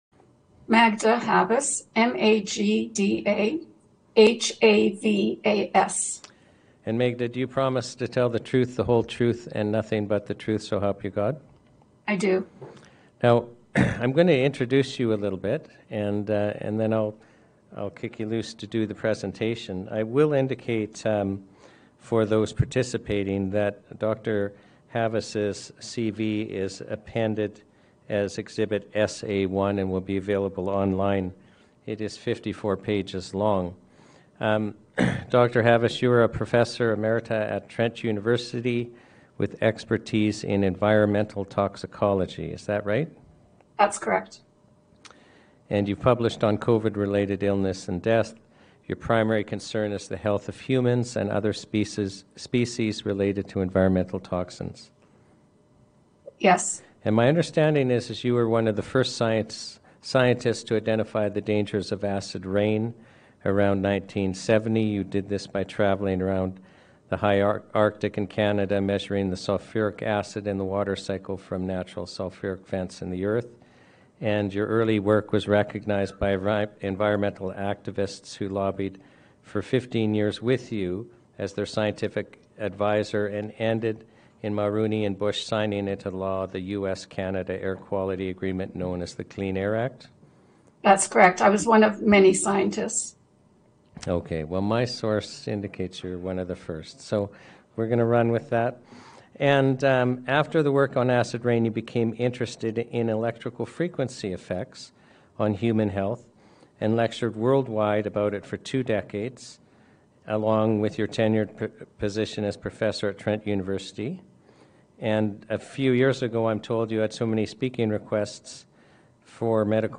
Saskatoon Day 3 - National Citizens Inquiry